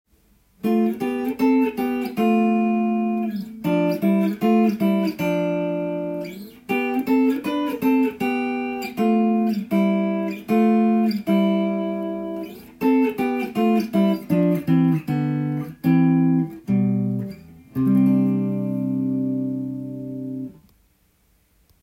6度ハモリを使うとバロック音楽と言われるバッハのようなクラシカルな
適当に弾くとこんな感じにも出来ますので
6do.hamori.solo_.m4a